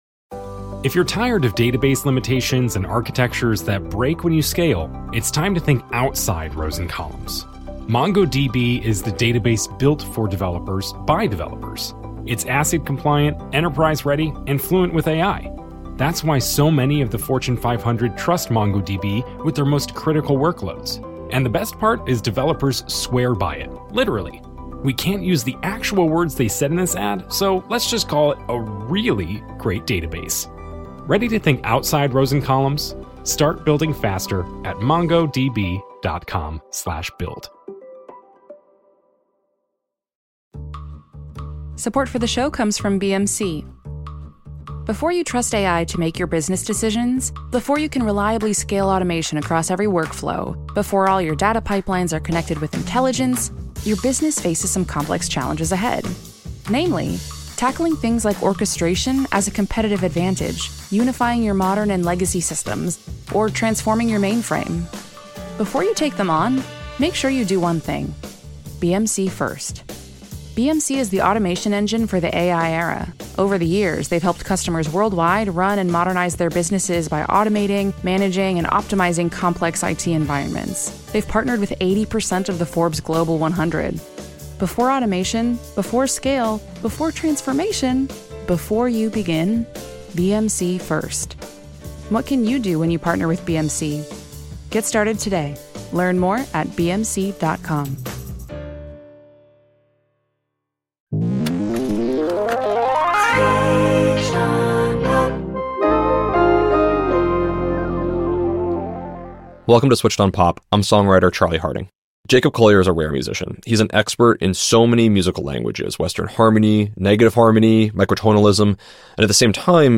Live at On Air Fest, this conversation, catches Jacob between projects.